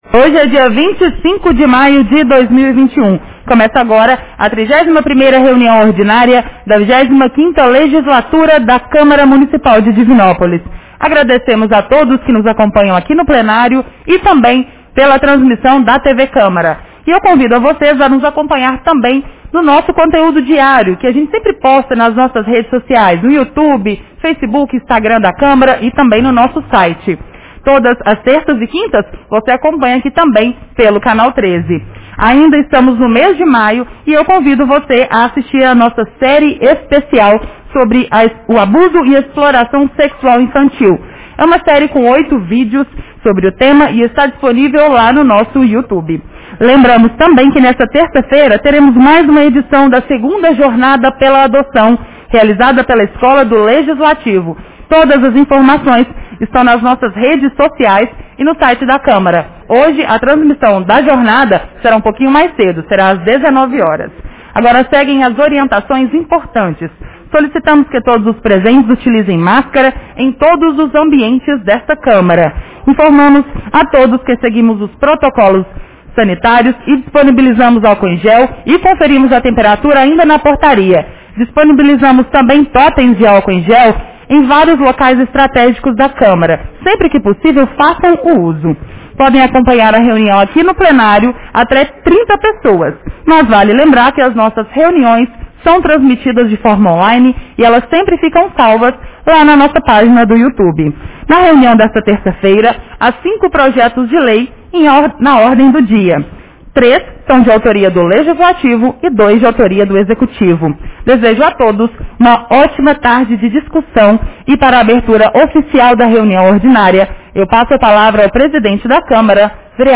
Reunião Ordinária 31 de 25 de maio 2021 — Câmara Municipal